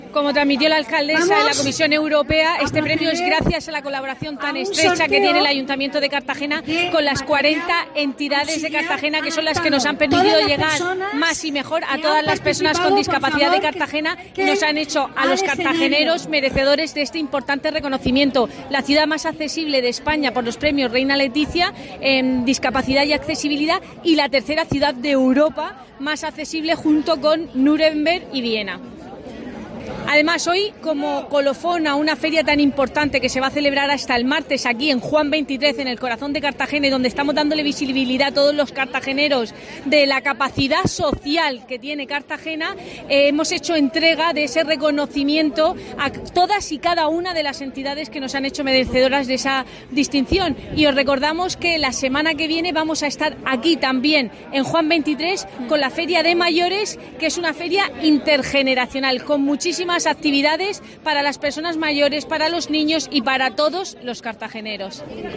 Enlace a Declaraciones de Cristina Mora.